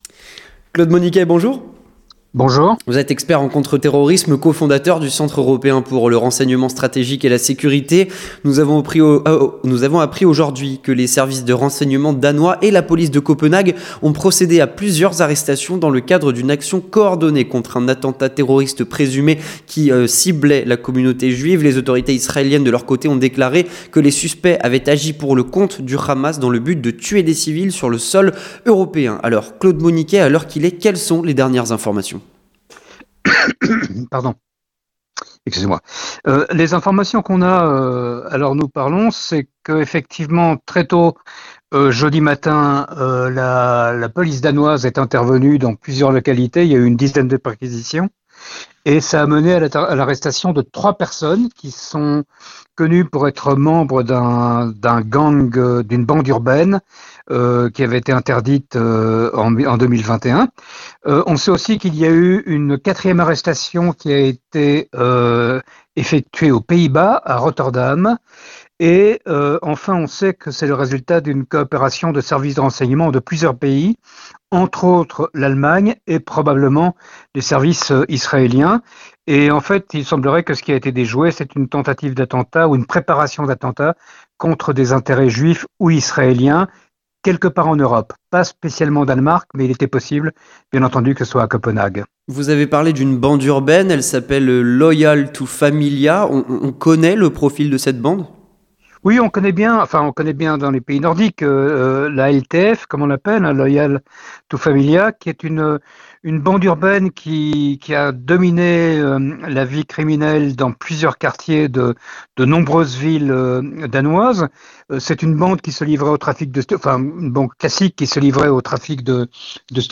3. L'entretien du 18h